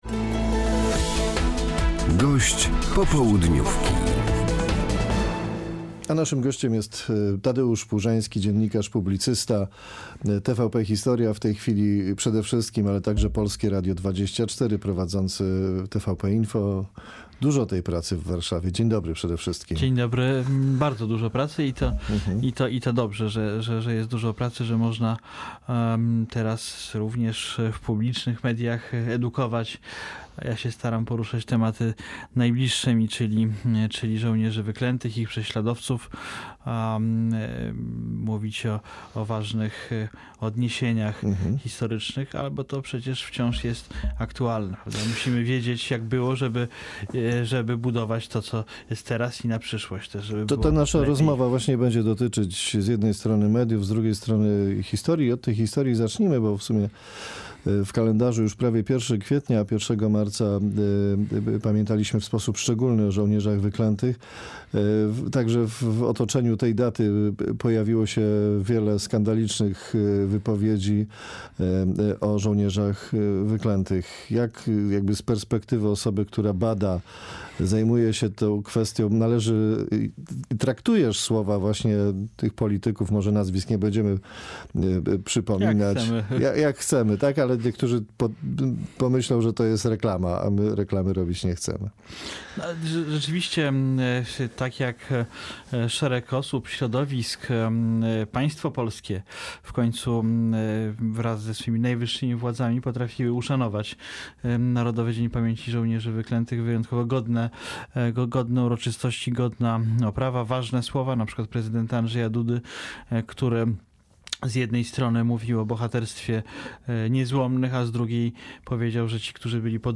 Z dziennikarzem, historykiem i publicystą